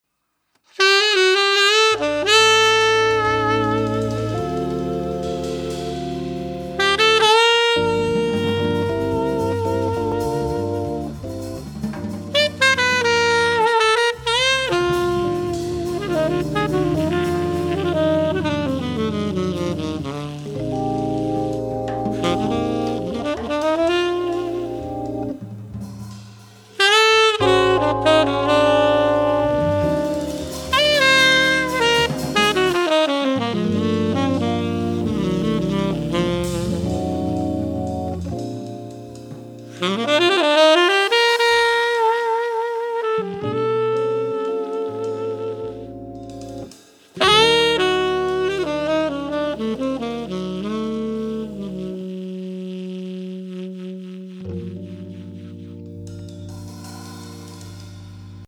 au sax
Belle maitrise du sax. beau son.
Qui est à l'orgue ?